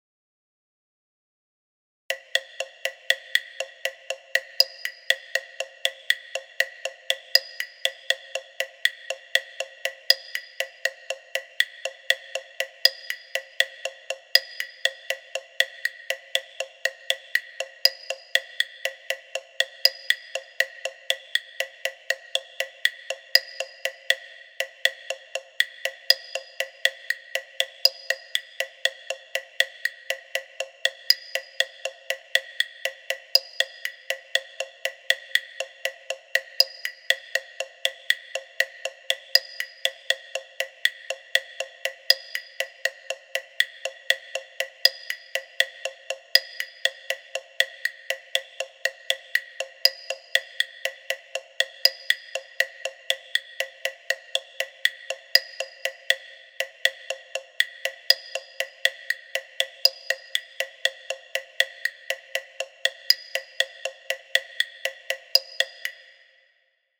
A composition etude using a family of five hypereuclidean sets with n=128.